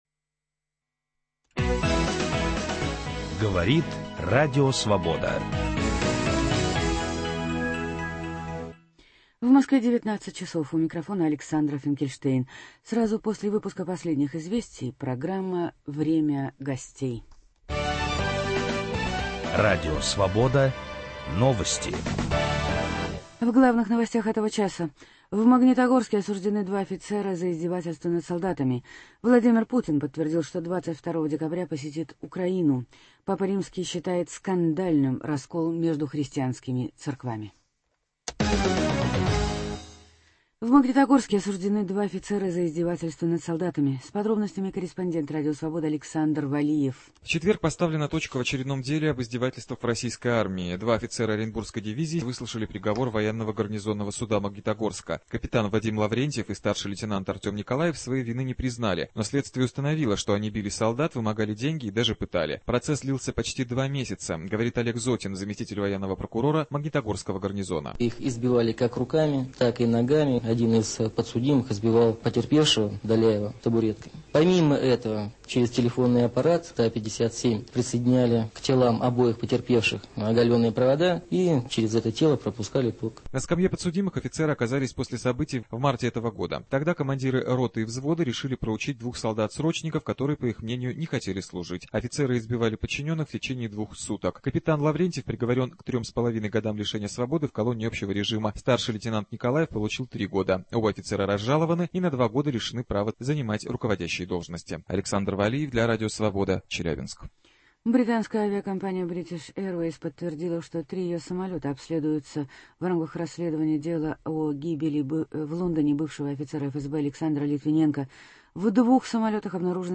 Притча называется «Парк советского периода». Режиссер фильма Юлий Гусман приглашен в студию программы «Время гостей».